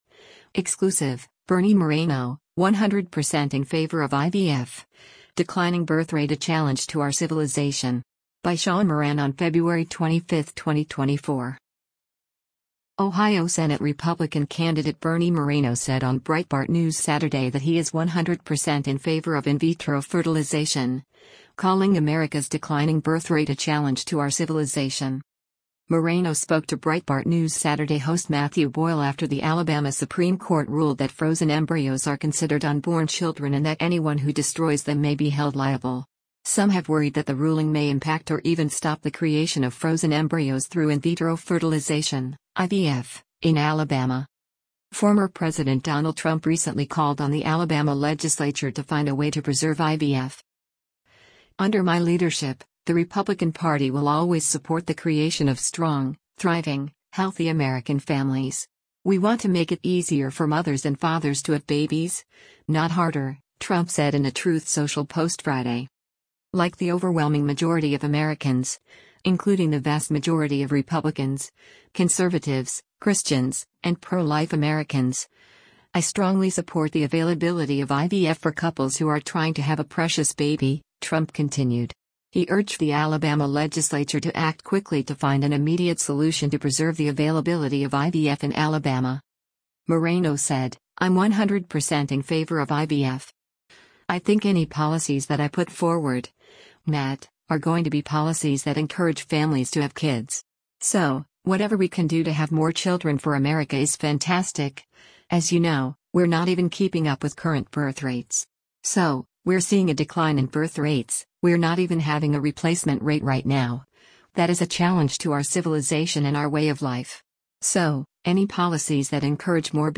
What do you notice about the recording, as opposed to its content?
Breitbart News Saturday airs on SiriusXM Patriot 125 from 10:00 a.m. to 1:00 p.m. Eastern.